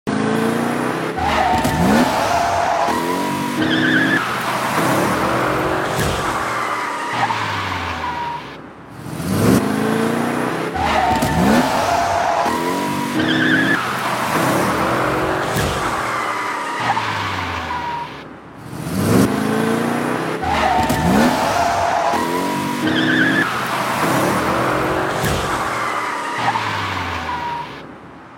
💨 Sound design for "Vroom" sound effects free download